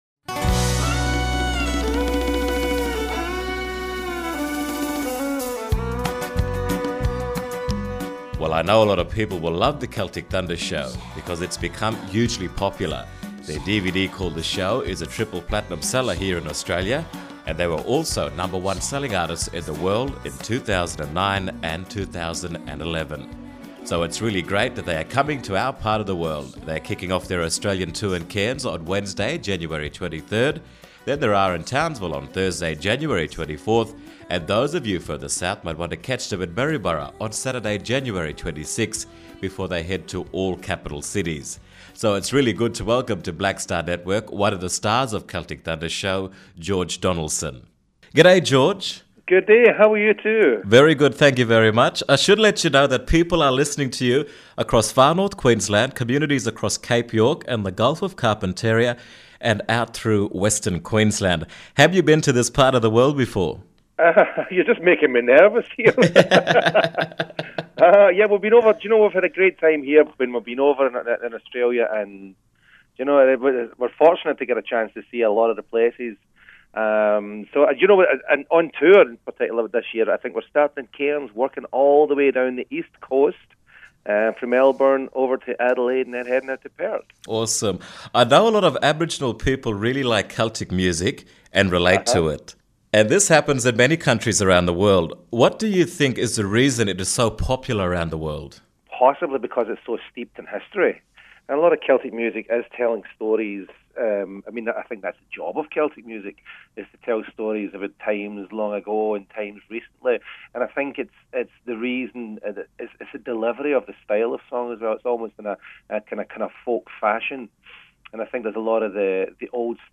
Celtic Thunder Interview
celtic-thunder-interview.mp3